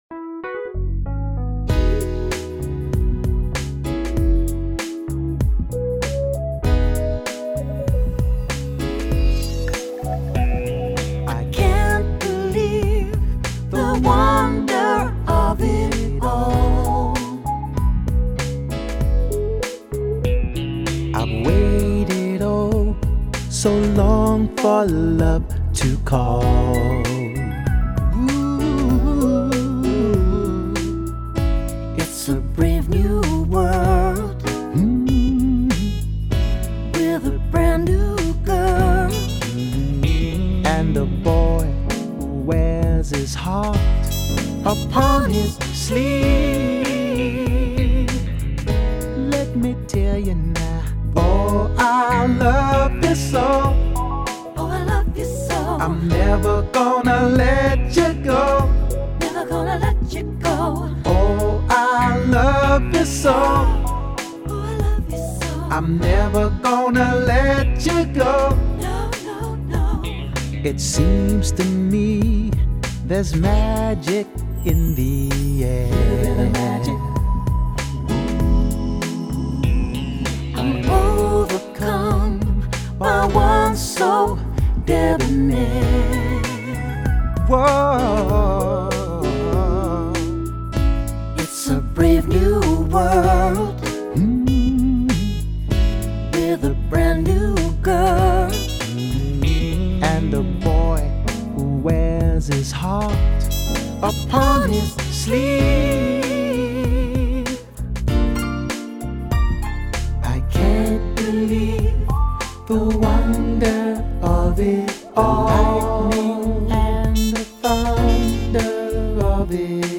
full vocal